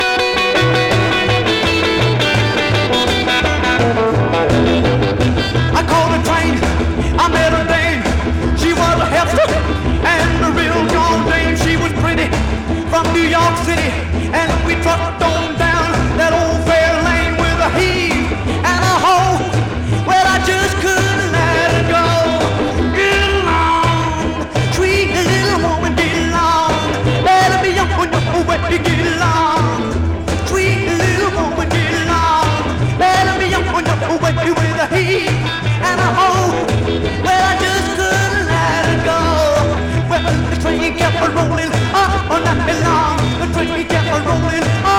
Rock, Rockabilly　Italy　12inchレコード　33rpm　Mono